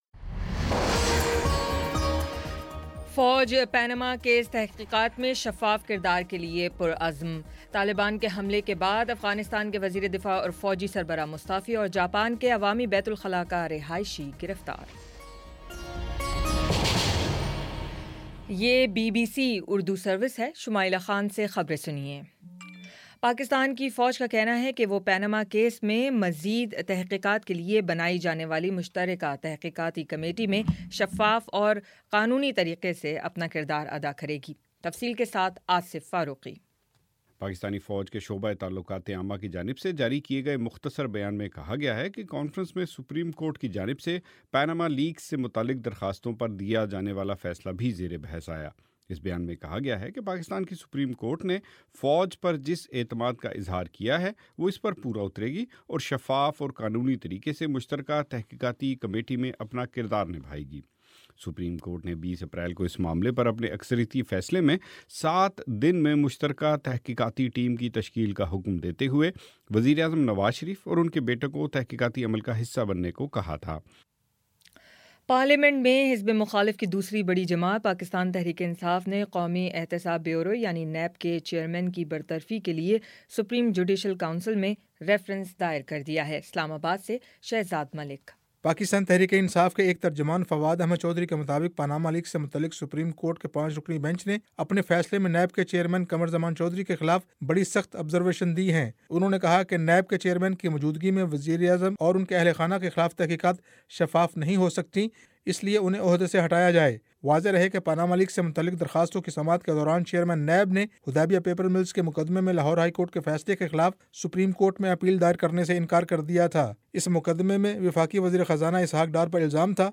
اپریل 24 : شام پانچ بجے کا نیوز بُلیٹن